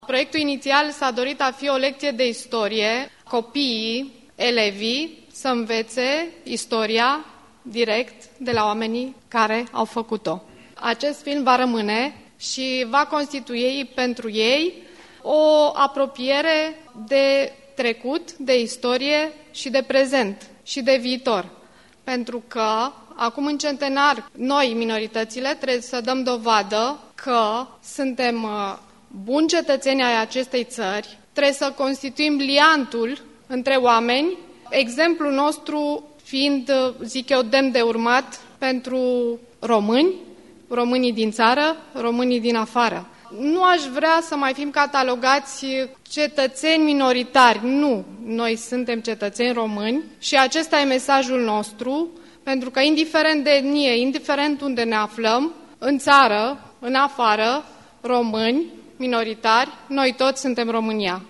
Asociația Macedonenilor din România (AMR) a organizat marți, 6 noiembrie 2018, în sala „Nicolae Bălcescu” a Parlamentului României, un eveniment dedicat sărbătoririi Centenarului Marii Uniri.
Discurs-venera-popescu.mp3